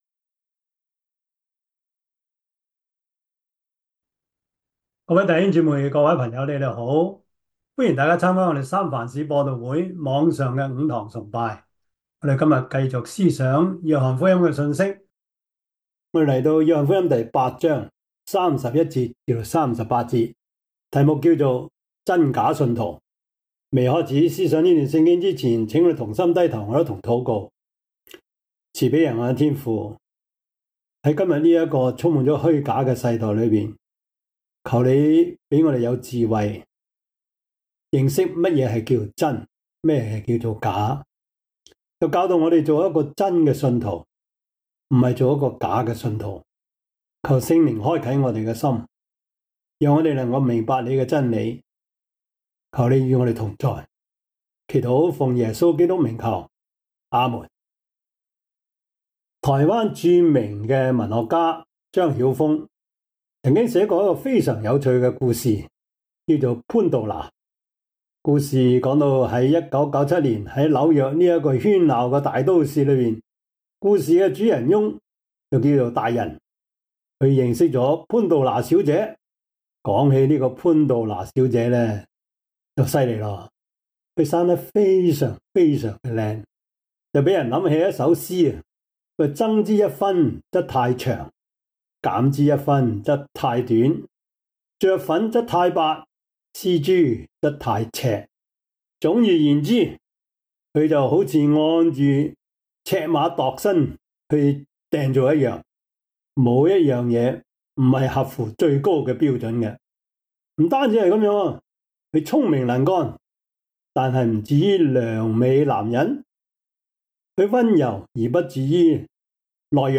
Service Type: 主日崇拜
Topics: 主日證道 « 新人事新作風 第五十課: 代孕的倫理問題 »